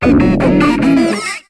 Cri de Grodrive dans Pokémon HOME.